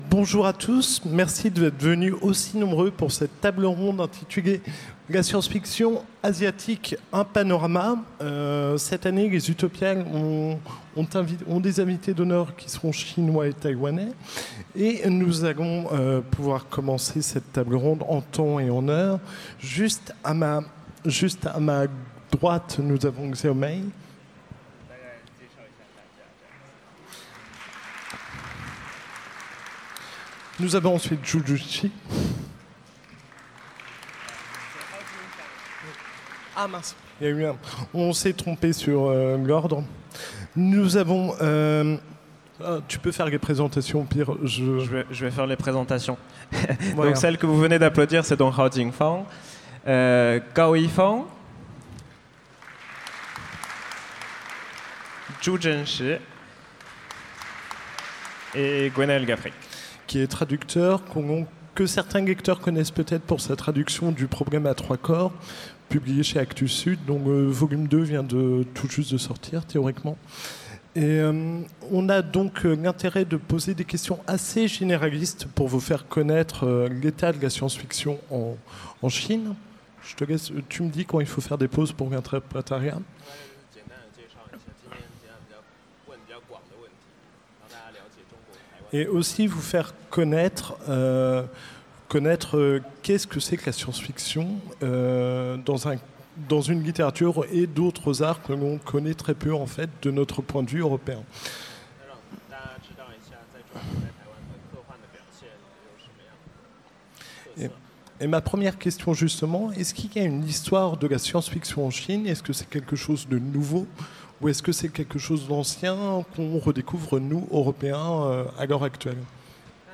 Utopiales 2017 : Conférence La science-fiction asiatique : panorama